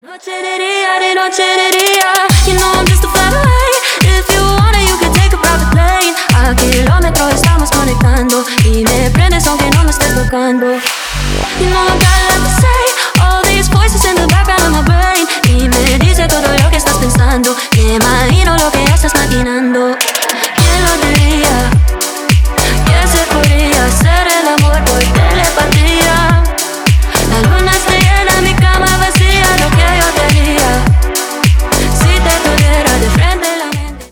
Танцевальные
клубные # кавер